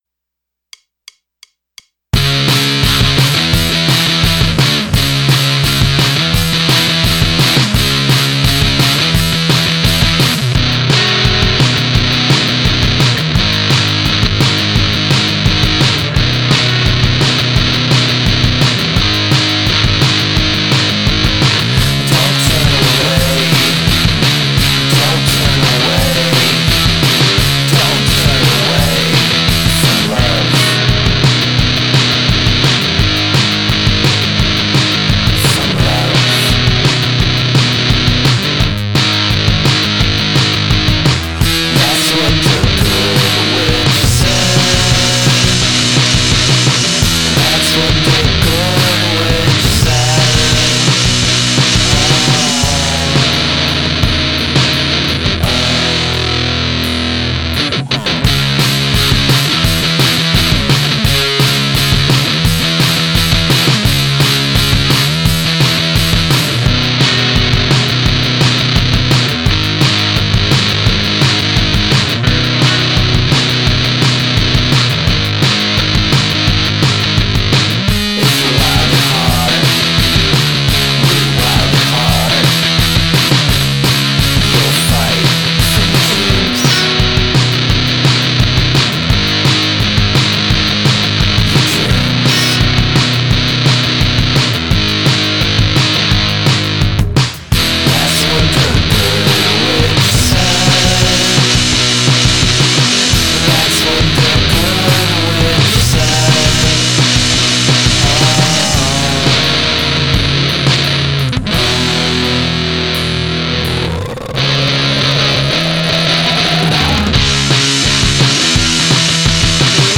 EP recorded spring 2025 at home in Toronto